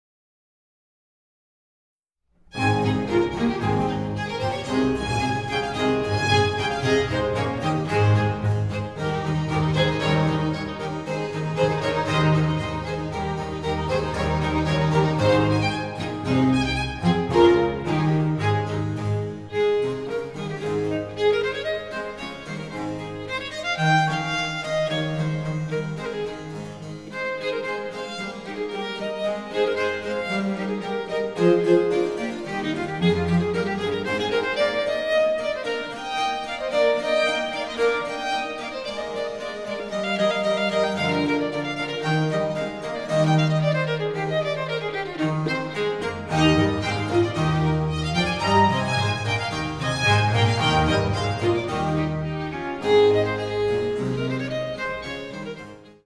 Ausschnitt-Concerto-A-Dur.mp3